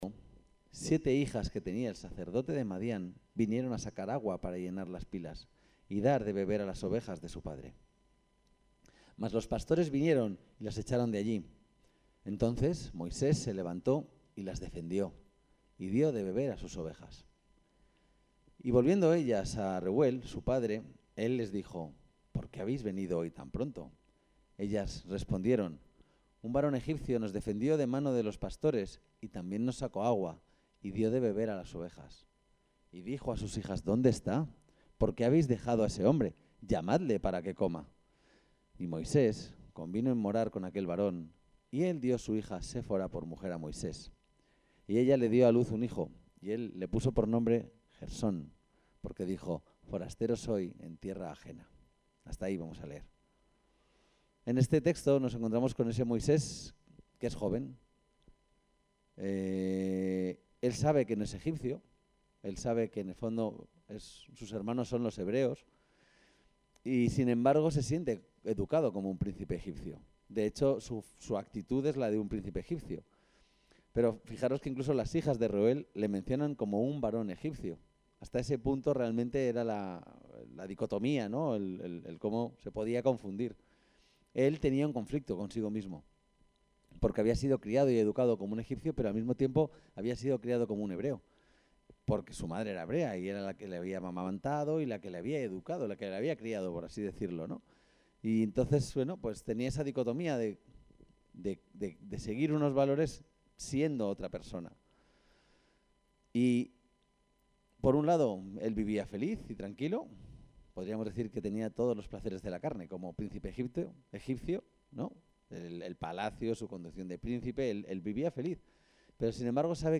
Aquí tenéis el texto de la predicación Apacienta a las ovejas